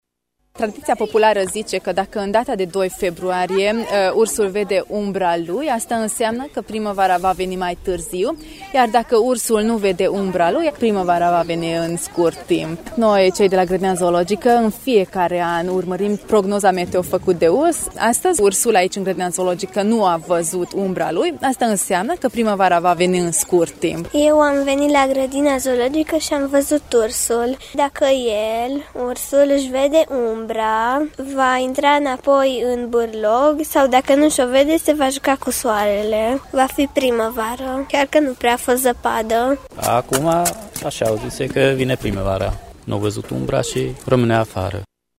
La ora 12 a avut loc mult așteptata prognoză meteo a urșilor care ies în padoc și ne dau de înțeles dacă se apropie sau nu primăvara: